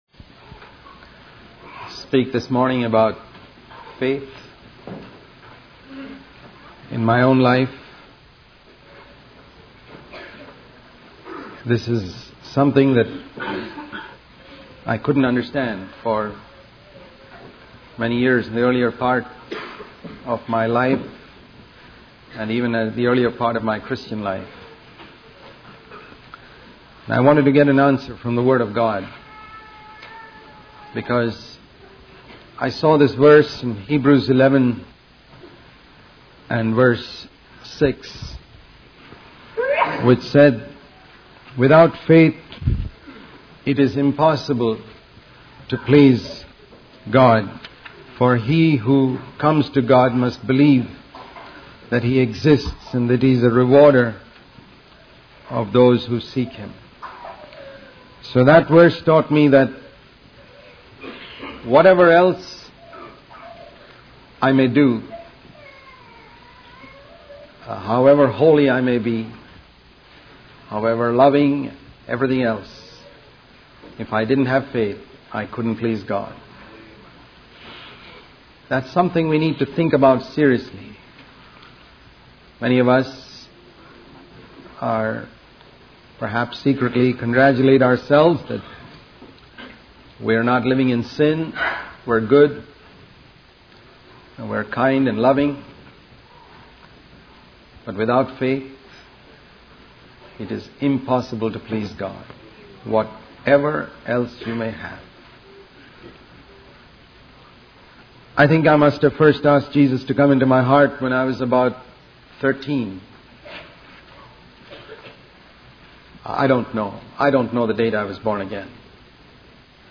In this sermon, the speaker emphasizes the importance of doing more and giving more in order to have God's presence in our lives. He uses the example of Zacchaeus, who went above and beyond to make sure he didn't cheat anyone and experienced salvation as a result.